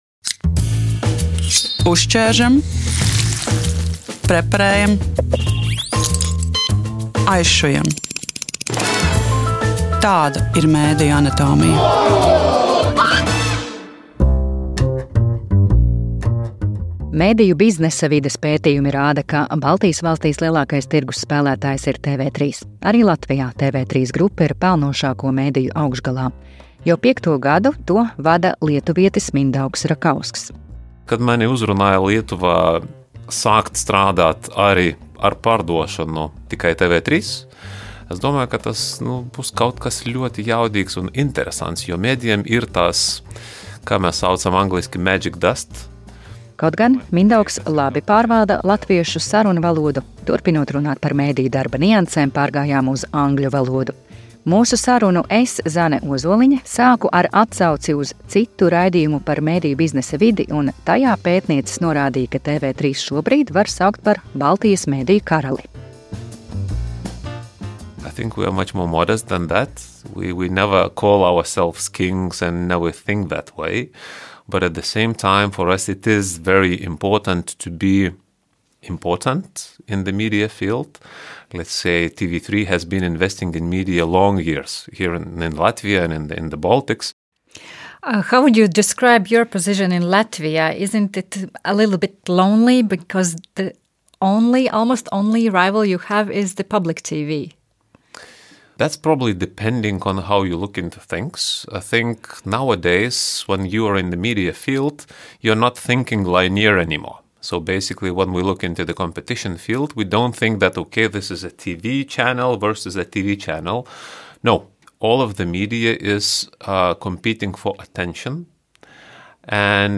[EN] TV3 vieta Latvijas tirgū un skatītāju izvēles Baltijā. Saruna